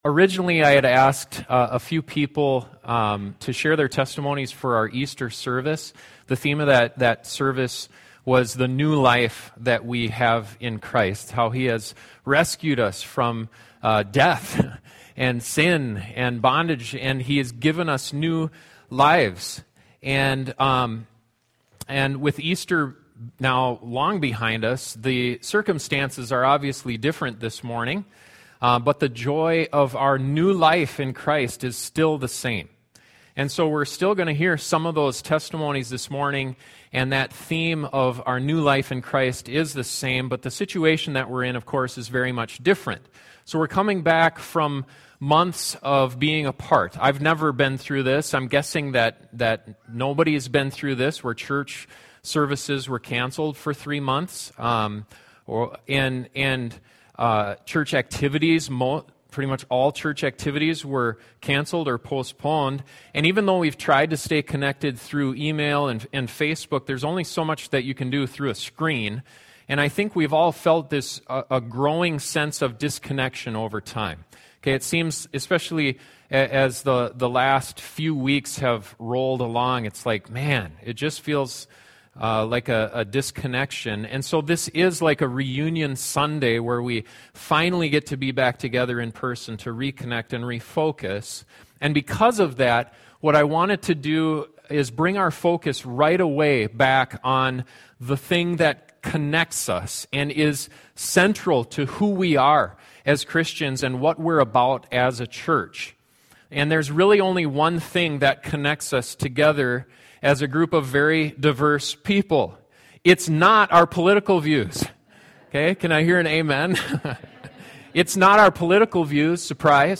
23-Reunite-and-Refocus-including-Testimonies.mp3